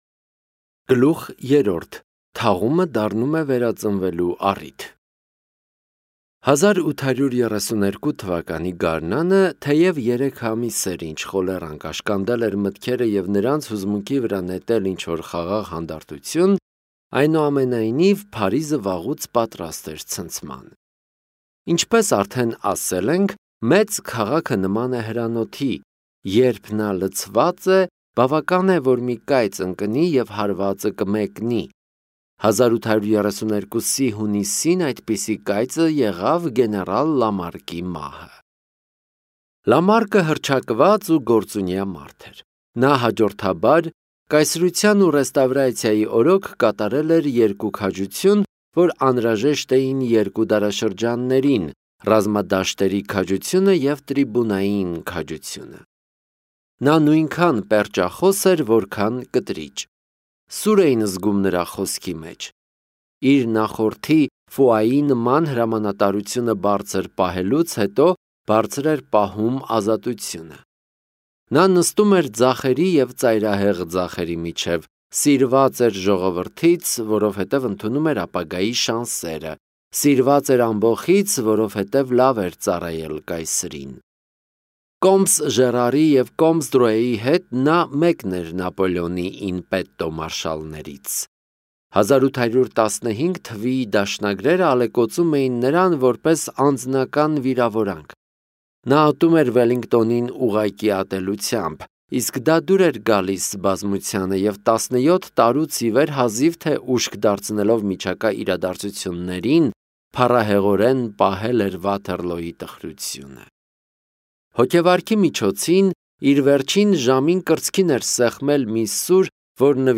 Հայերեն ձայնային գրքեր, աուդիոգրքեր, Armenian audiobooks